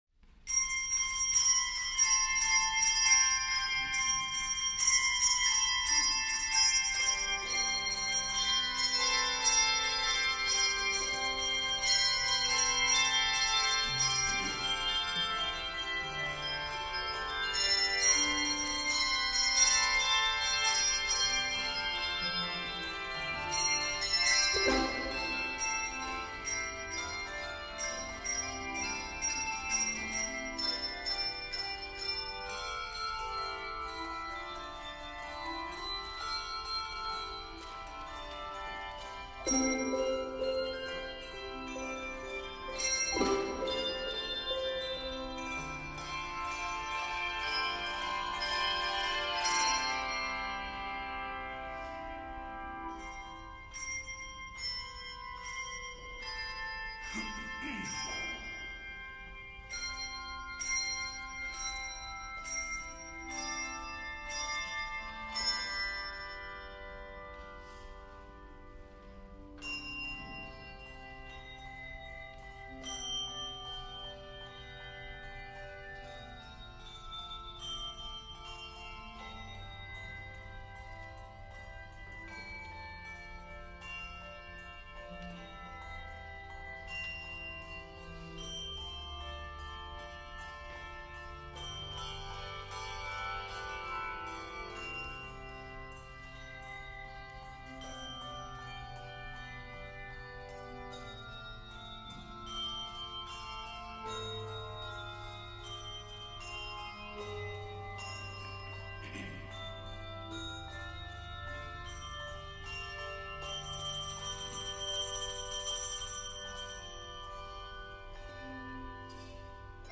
This piece is 86 measures and is scored in C Major.